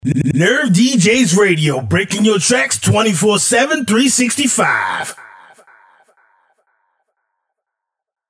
Acapellas